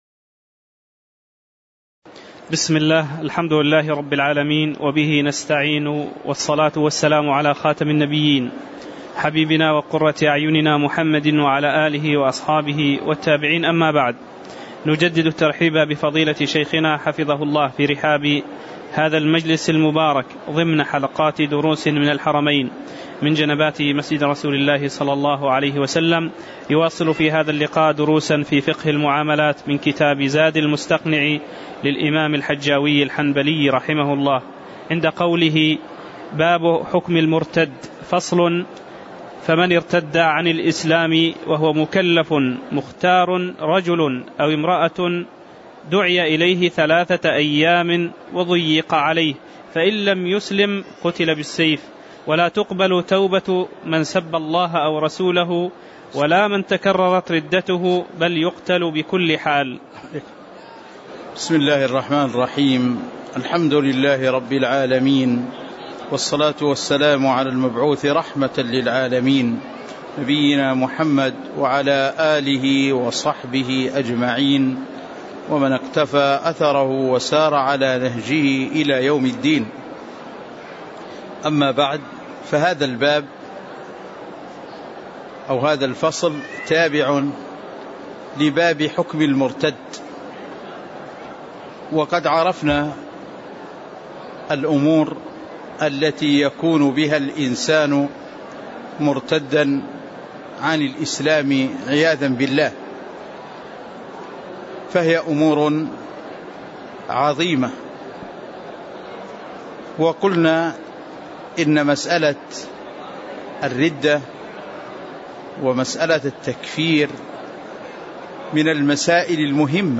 تاريخ النشر ٨ جمادى الآخرة ١٤٣٨ هـ المكان: المسجد النبوي الشيخ